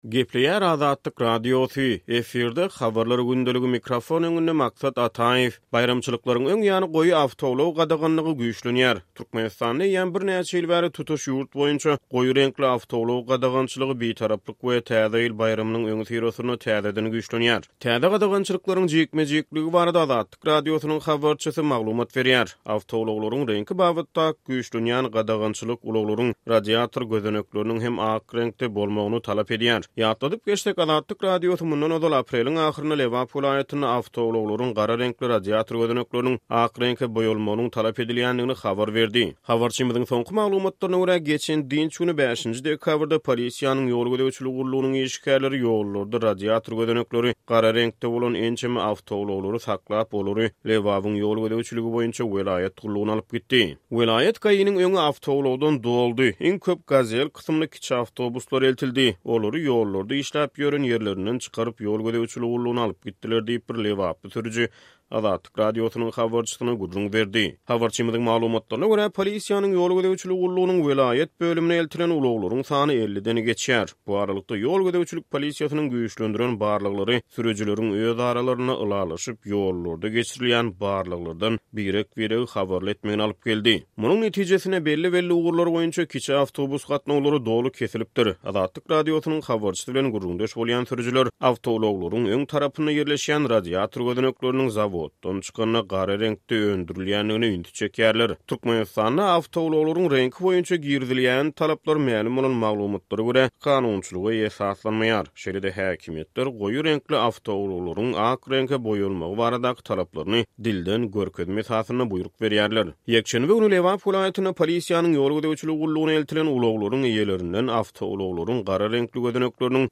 Türkmenistanda eýýäm birnäçe ýyl bäri tutuş ýurt boýunça goýy reňkli awtoulag gadagançylygy, Bitaraplyk we Täze ýyl baýramynyň öňüsyrasynda täzeden güýçlenýär. Täze gadagançylyklaryň jikme-jikligi barada Azatlyk Radiosynyň habarçysy maglumat berýär.